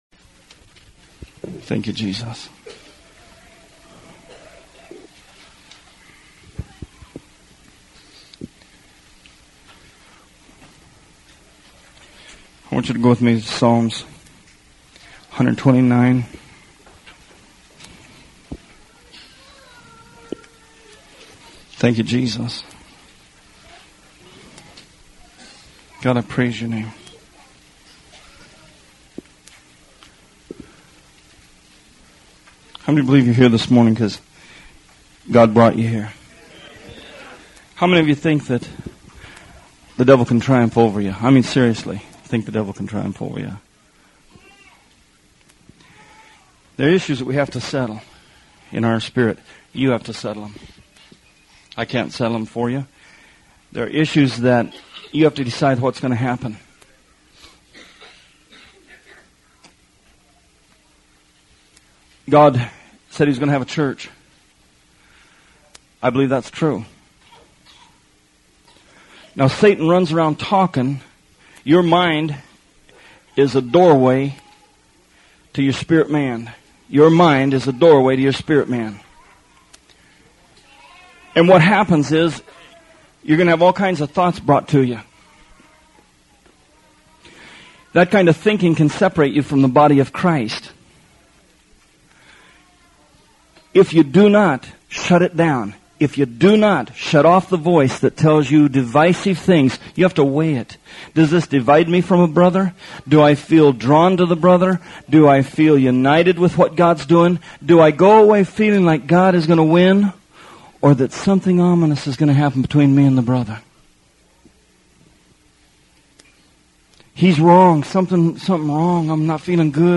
Songs of Degrees: This is a sermon series about Psalms 120 through 134.